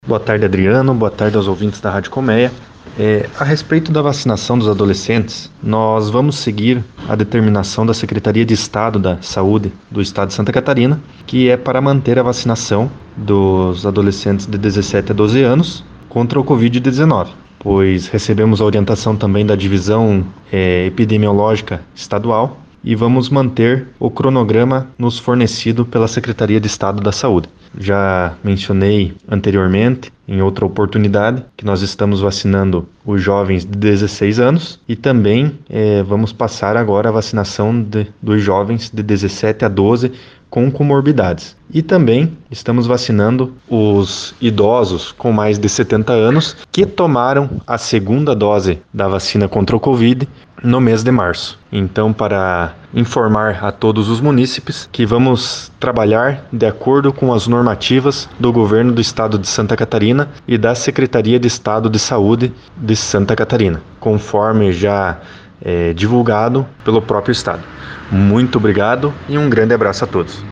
Ouça o áudio do secretário Ruan Wolf: